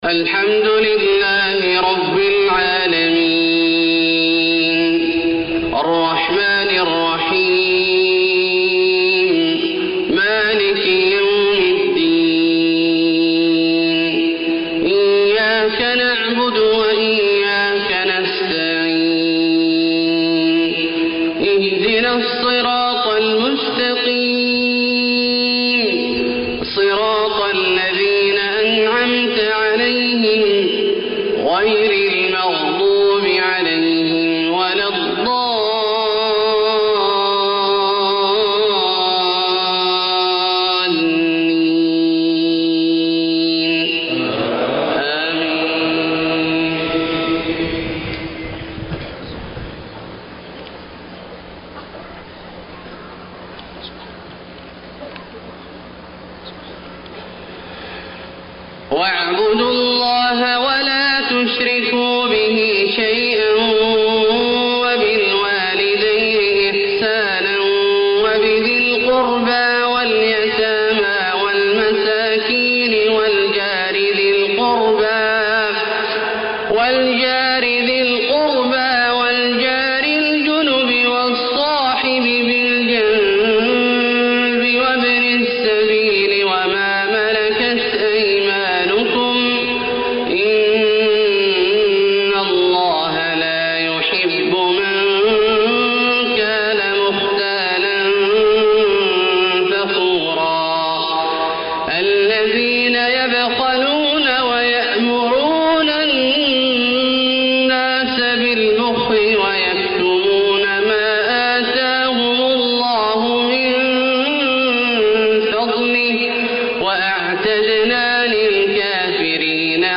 Fajr prayer 6-3-2012 | Surah AnNisa > 1433 H > Prayers - Abdullah Al-Juhani Recitations